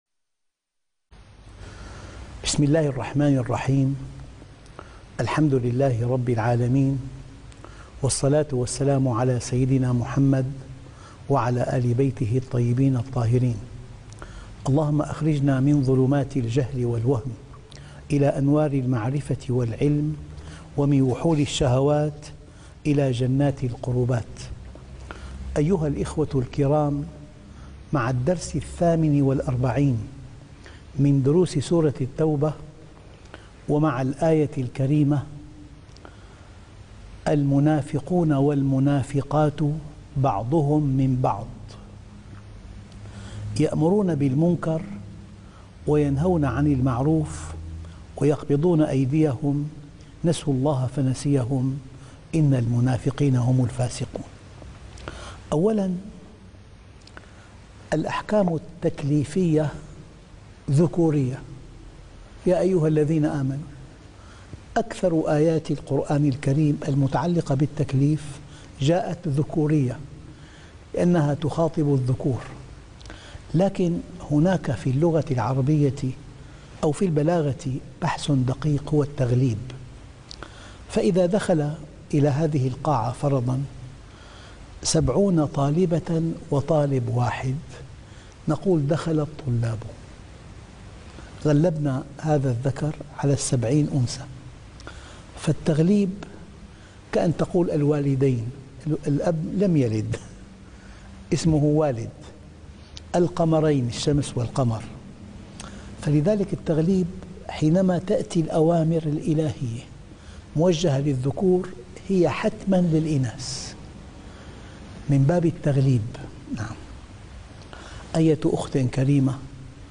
الدرس ( 48) تفسير سورة التوبة - الشيخ محمد راتب النابلسي